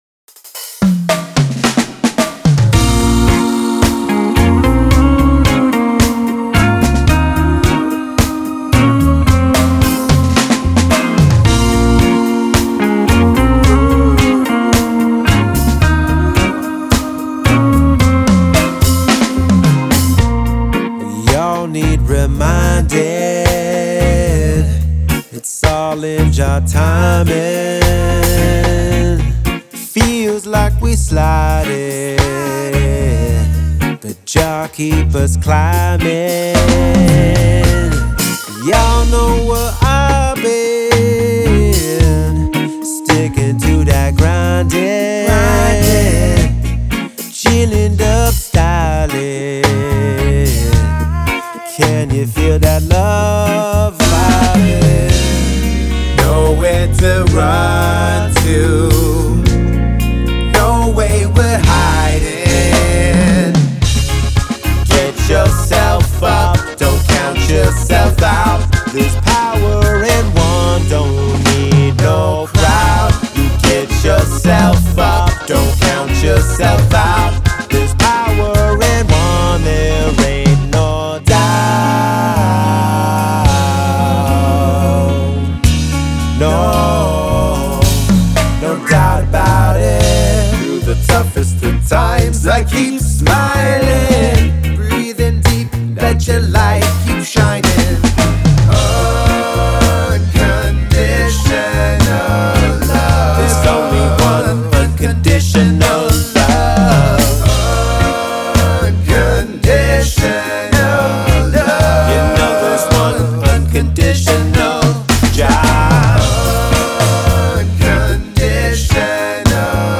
” a roots reggae anthem about rising up
With meditative dub grooves, warm vocal harmonies
known for his soulful baritone and Cali-style grooves.
Reggae Rock band